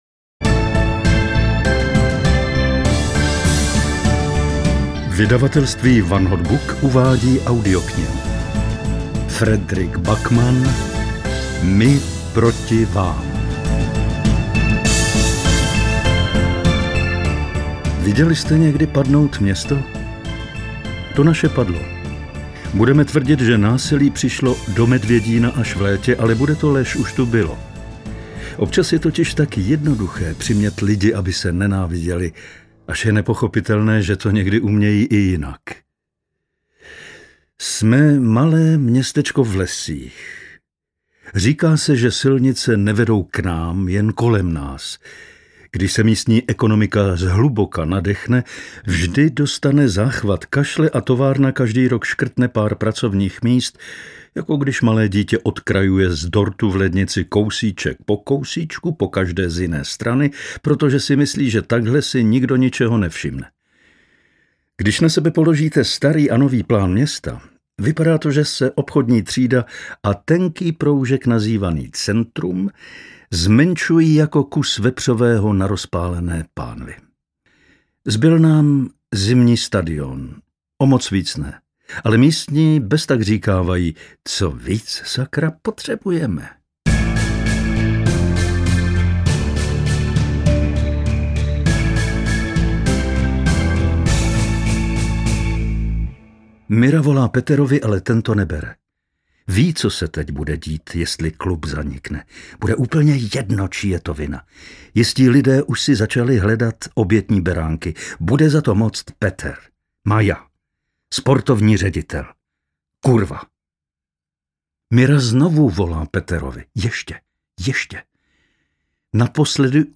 Pracuje s odmlkami, vypomáhá si, citlivě, i s řečovými gesty, která od sebe odlišují jednotlivé aktéry.“
Dokázal dodat napětí tam, kde to bylo potřeba a také zachytit sportovní dravost. Jeho hlas je hřejivý a příjemný, také jej často mění a přenáší jeho prostřednictvím mnoho emocí.